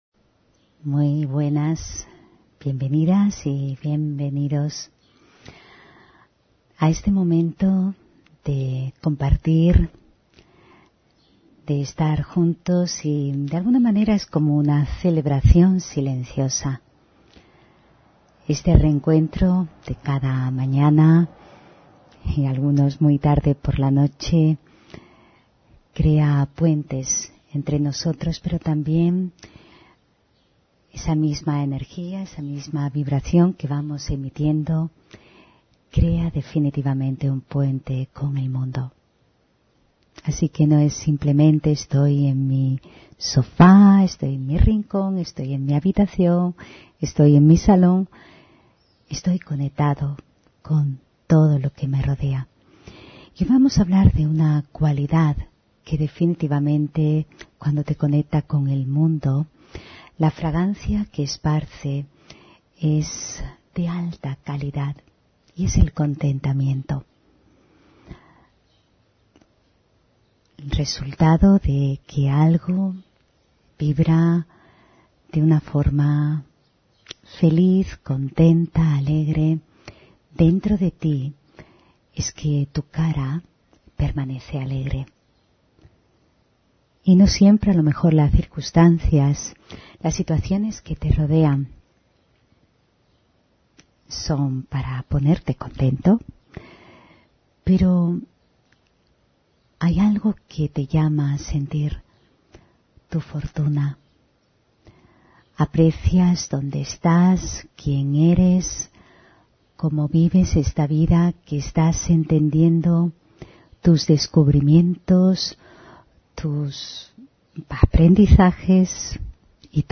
Meditación de la mañana: El contentamiento , la música del alma